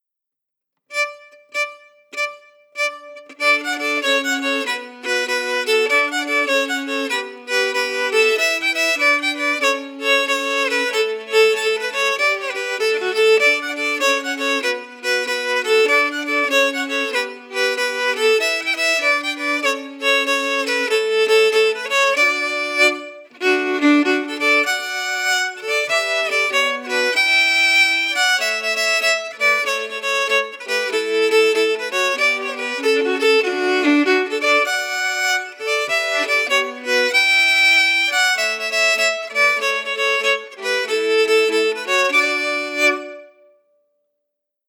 Key: D
Form: Québecois six-huit (Jig)
Genre/Style: Québecois six-huit
Quadrille-Bouchard-audio-file-melody.mp3